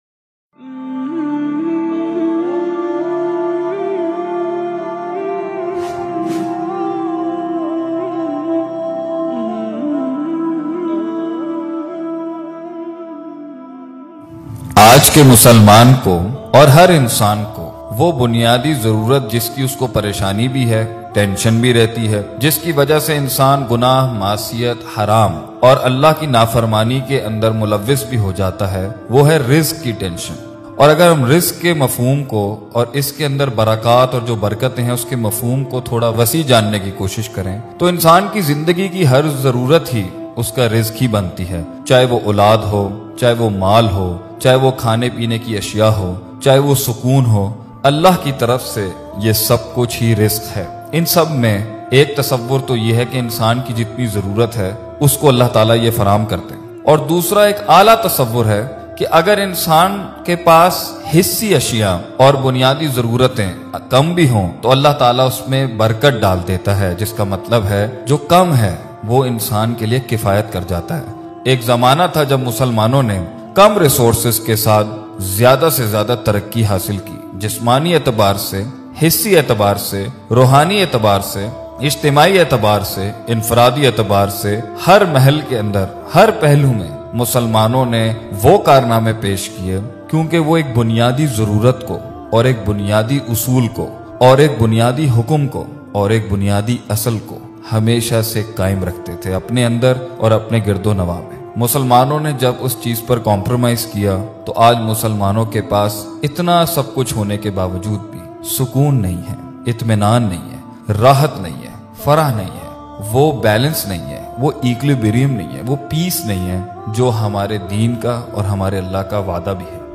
Rizq Me Barkat Motivational Speech